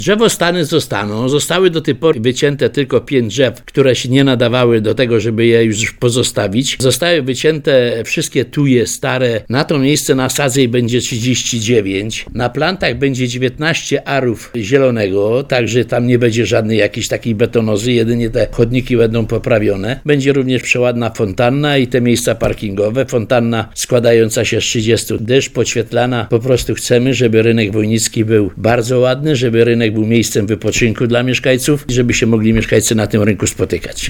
Jak mówi Radiu RDN Małopolska burmistrz Wojnicza Tadeusz Bąk, to duża radość, że udało się po długim czasie rozpocząć rewitalizację, a same utrudnienia nie potrwają długo.